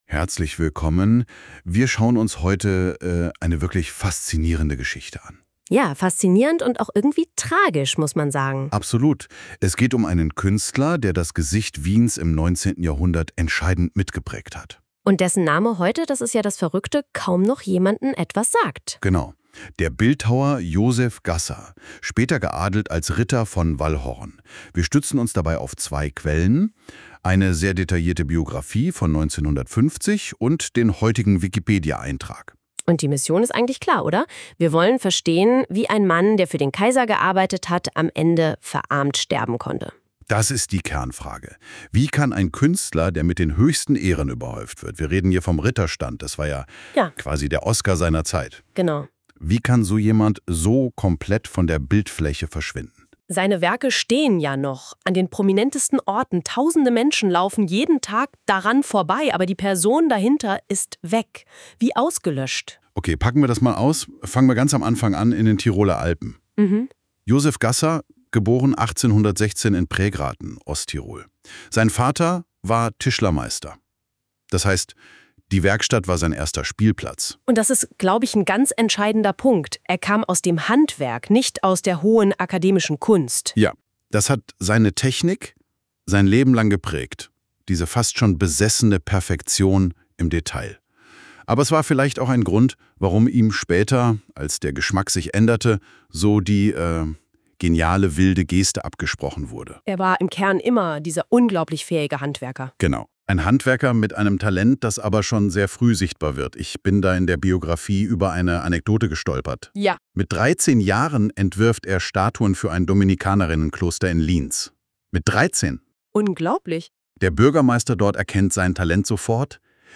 Hör dir die Zusammenfassung an. (Hinweis: Audio von KI generiert) 0:00 Präsentation ansehen (Hinweis: Präsentation als PDF) PDF öffnen